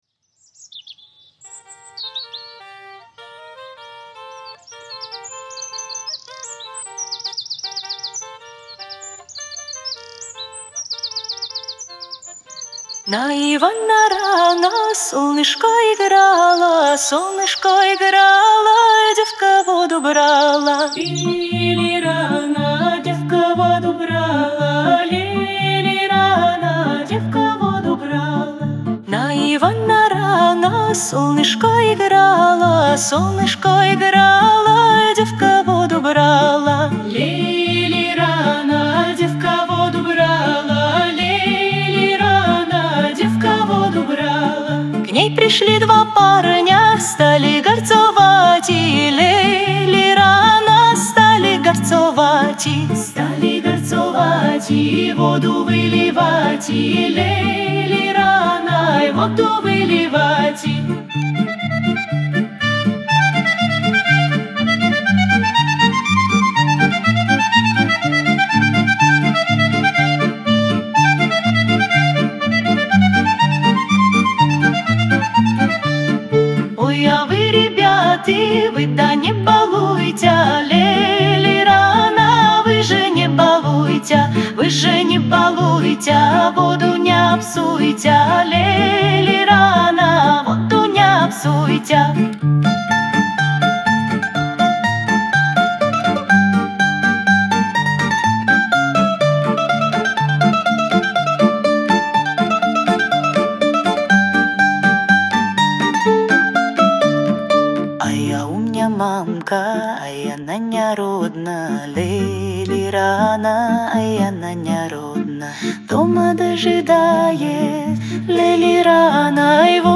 Народные песни праздника Ивана Купала,
Яркая зарисовка из народной жизни. Текст наполнен диалектными словами, все в целом понятны, за скобками пока осталось выражение «воду не апсуйтя«.